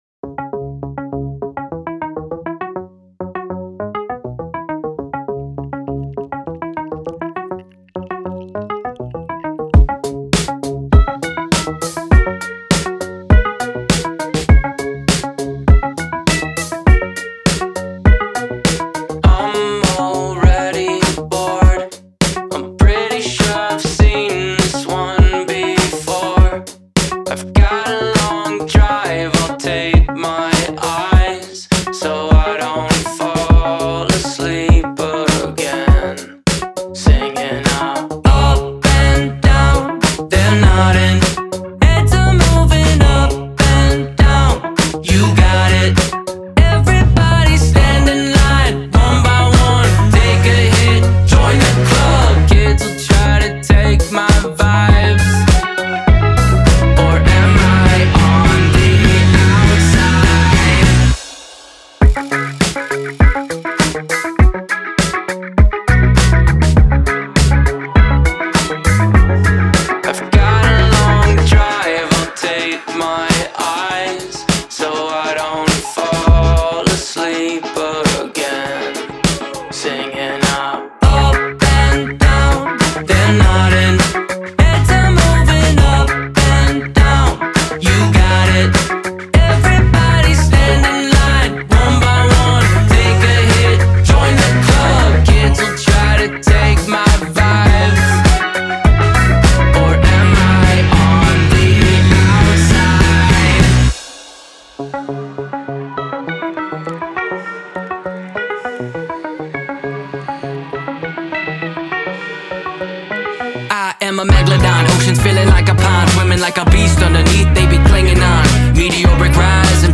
Genre : Indie Pop, Alternative